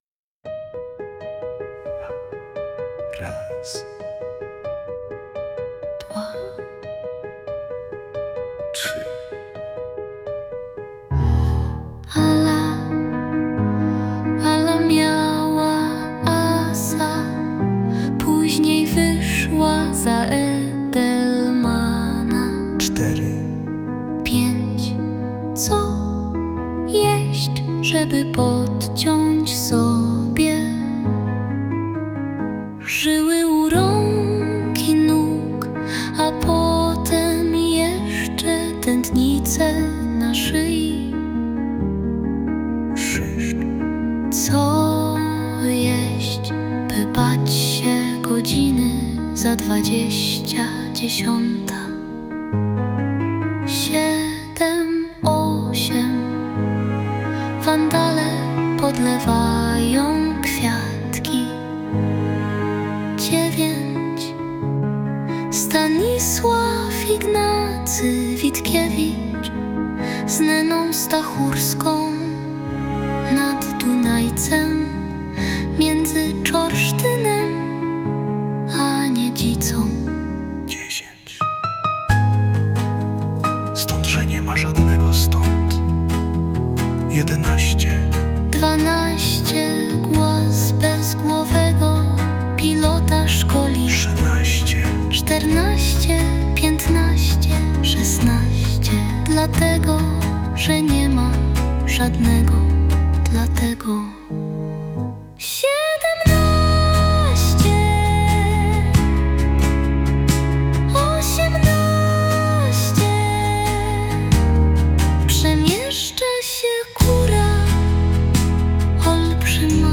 poezja
live